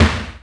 cch_08_snare_one_shot_mid_lofi_slam.wav